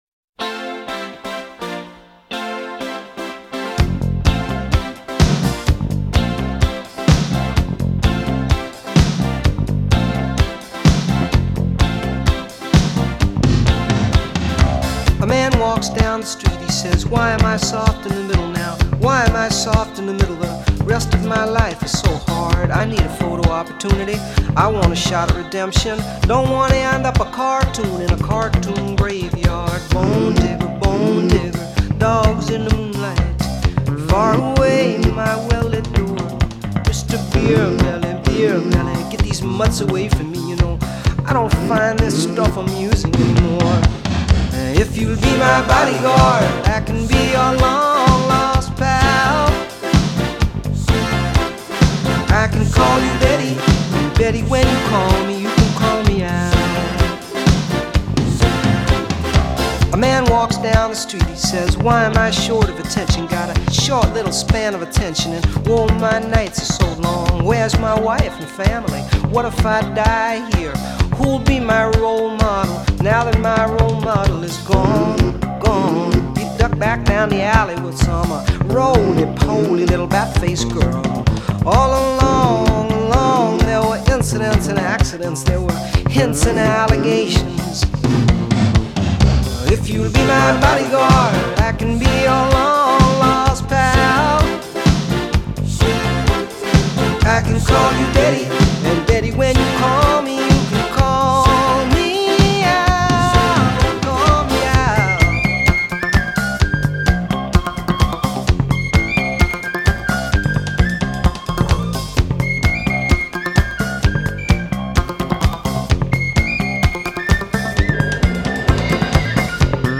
Жанр: Folk Rock, Folk, Pop